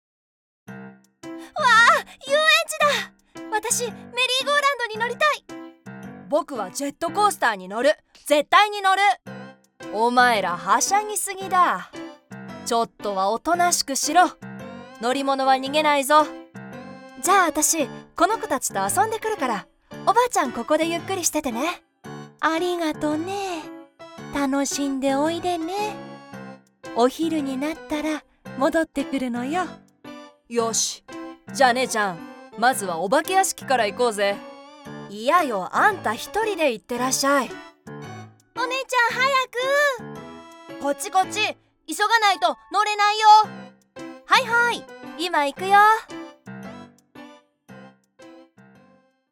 Videojuegos
Con mi estudio en casa equipado con equipos de primer nivel, estoy lista para darle vida a tu proyecto.
Una voz nítida que cautiva a tu audiencia
Conversacional, versátil, elegante, lujosa, auténtica, autoritaria, conversacional, profunda, confiable, optimista, amigable y creíble.
Micrófono: NEUMANN TLM102